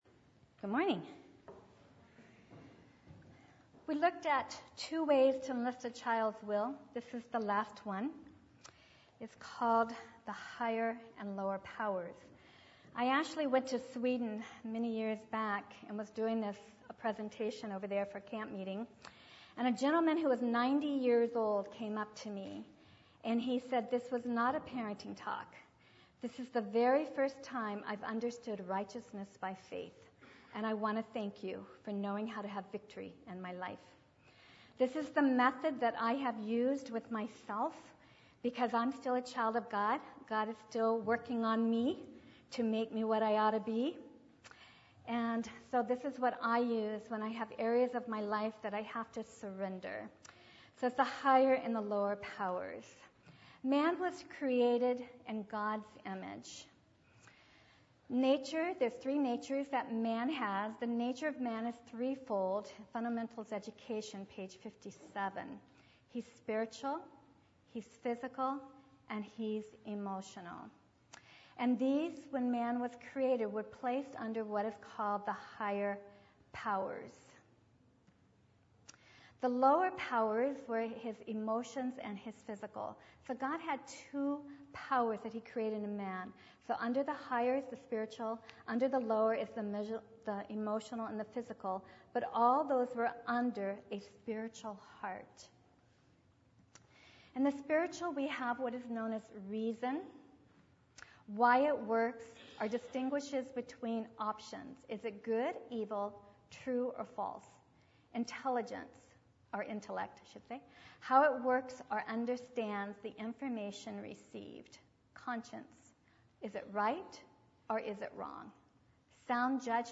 Parenting Seminar Service Type: Sabbath Download Files Bulletin « 3 Ways to Enlist a Child’s Will